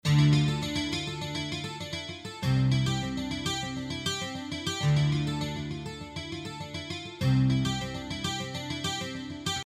中世纪吉他循环
描述：另一个很棒的循环，有一些中世纪的风格，在100bpm，这是用propellerheads reason 4制作的。
Tag: 100 bpm Acoustic Loops Guitar Electric Loops 1.62 MB wav Key : Unknown